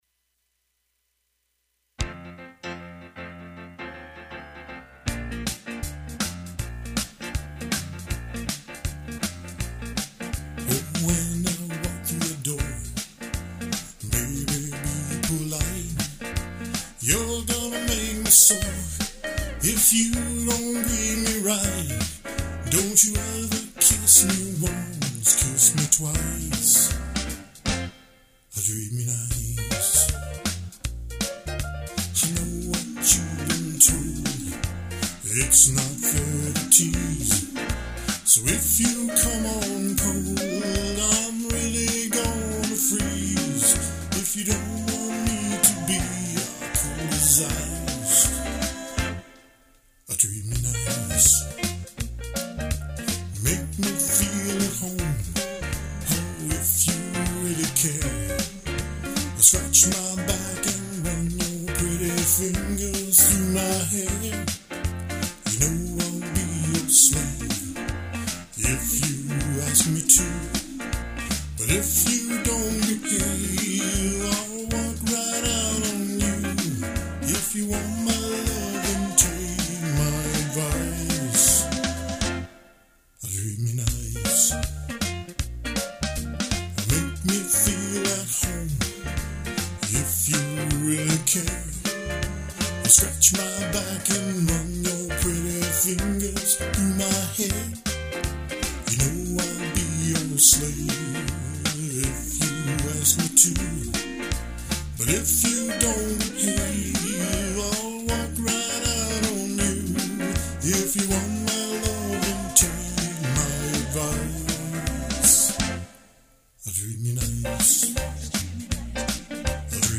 Drum machine